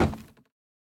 Minecraft Version Minecraft Version 1.21.5 Latest Release | Latest Snapshot 1.21.5 / assets / minecraft / sounds / block / bamboo_wood / step4.ogg Compare With Compare With Latest Release | Latest Snapshot
step4.ogg